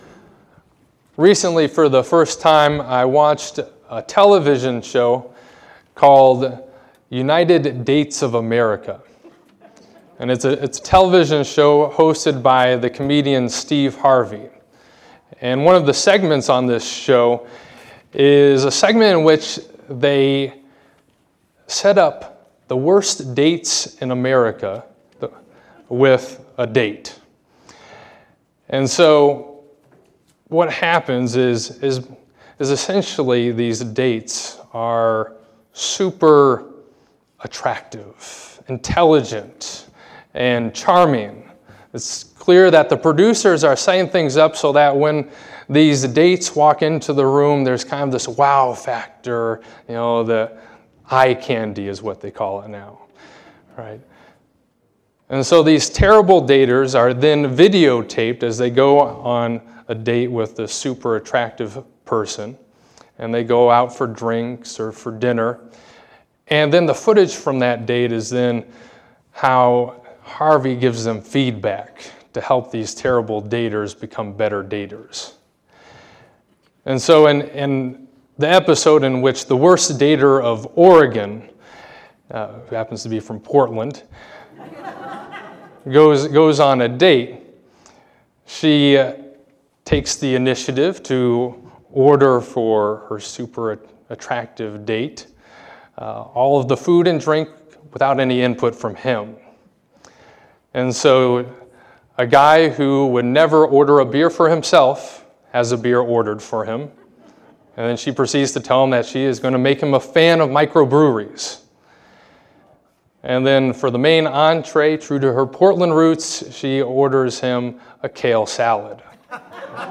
Scripture Reading—Hosea 2: 14-23